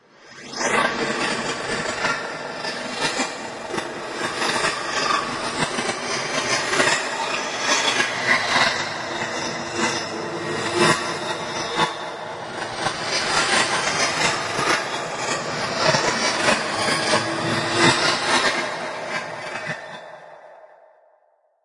描述：我在测试一些VSTis和VSTs，并录制了这些样本。加入了一些Audition魔法。 越来越多的混响和延迟，包括前向和后向.
Tag: 混响 噪音 恐怖 回声